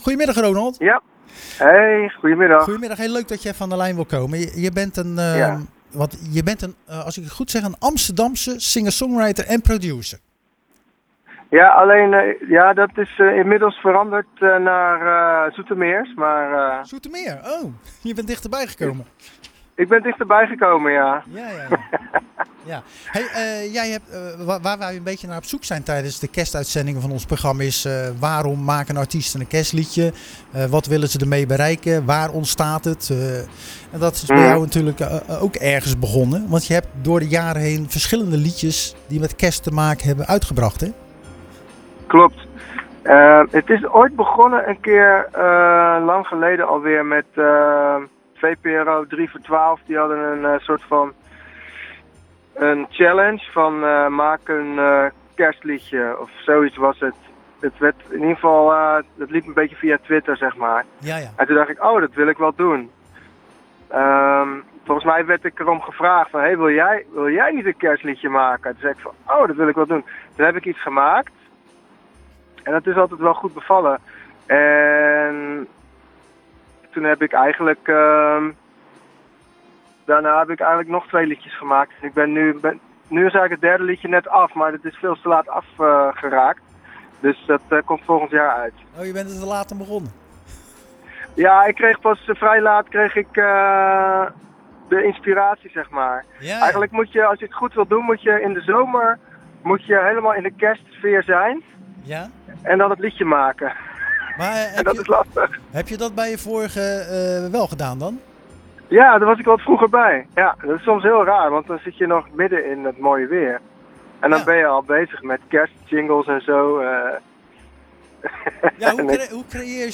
Tijdens de jaarlijkse kerstuitzending spraken we singer-songwriter en producer